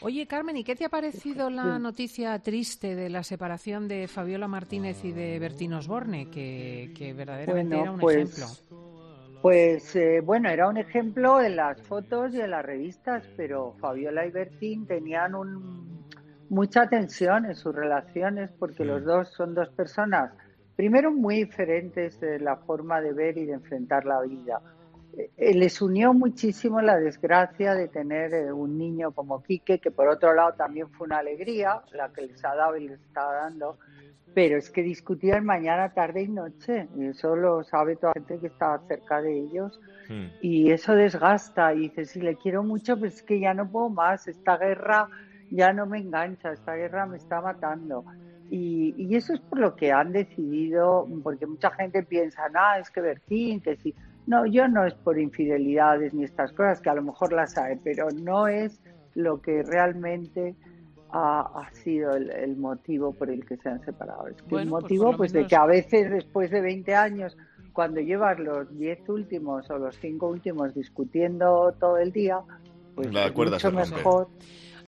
La colaboradora de Fin de Semana, Carmen Lomana, no se ha mordido la lengua este sábado a la hora de hablar de la sorprendente ruptura de Bertín Osborne con Fabiola Martínez tras 20 años juntos.